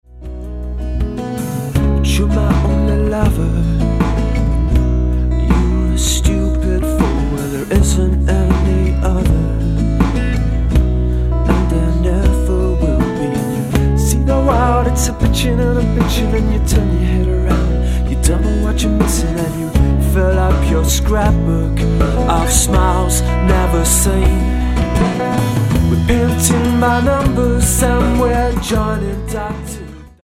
Studio recordings
Lead vocals, guitar, backing vocals
Lead guitar, bass, drum programming, backing vocals